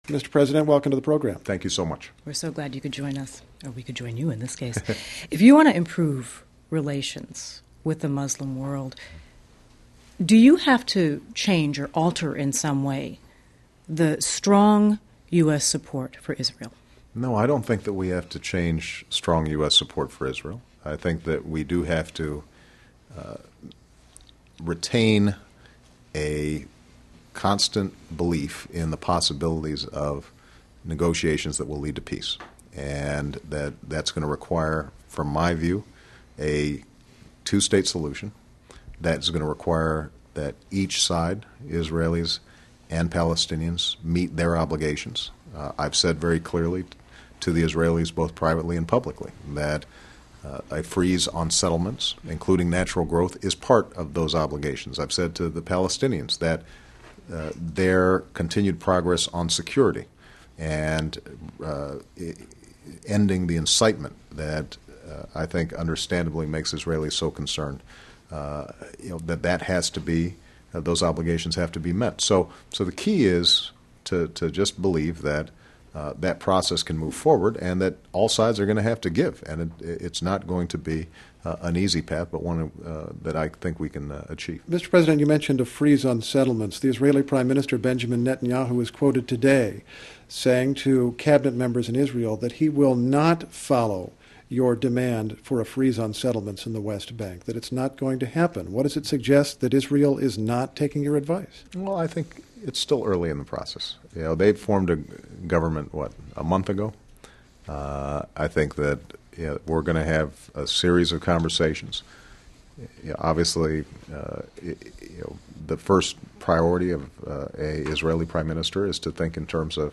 2009 Share President Obama met with NPR's Michele Norris and Steve Inskeep Monday to discuss his Mideast policies before he delivers a speech in Cairo on Thursday.